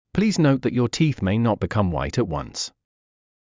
ﾌﾟﾘｰｽﾞ ﾉｰﾄ ｻﾞｯﾄ ﾕｱ ﾃｨｰｽ ﾒｲ ﾉｯﾄ ﾋﾞｶﾑ ﾎﾜｲﾄ ｱｯﾄ ﾜﾝｽ